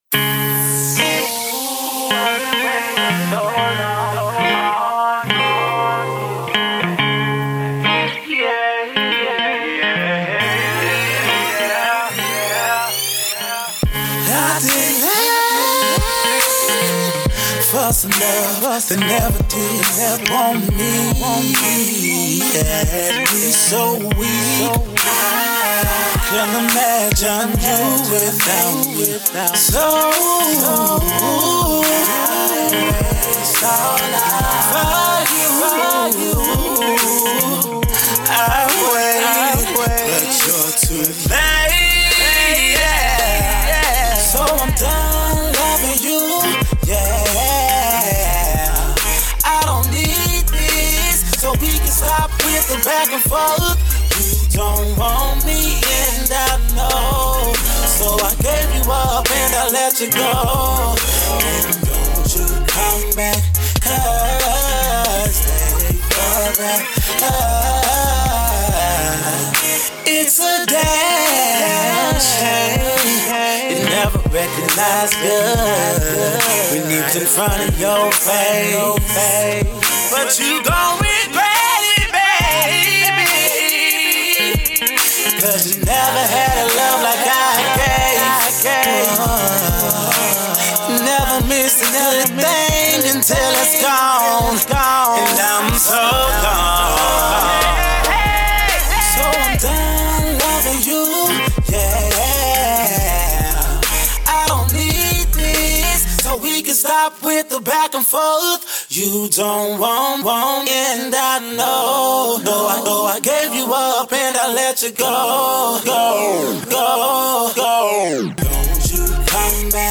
Mixing a Soulful vibe with a Youthful execution